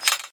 aug_a3_switch.ogg